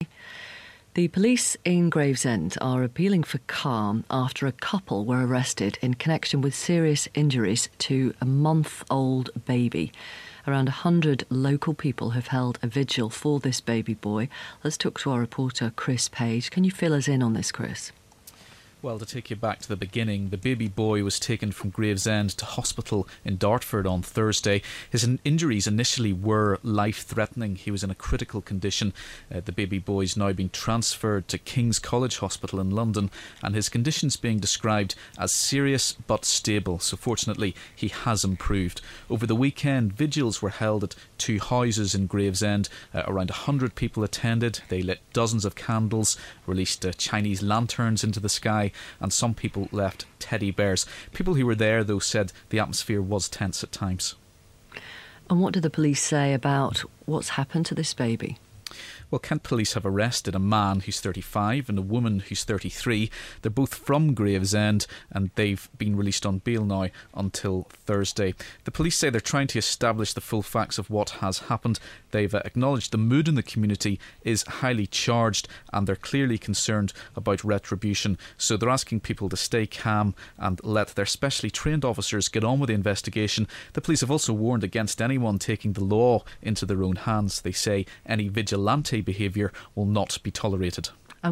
2way with Victoria Derbyshire on disturbing case of an assault on a baby.